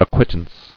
[ac·quit·tance]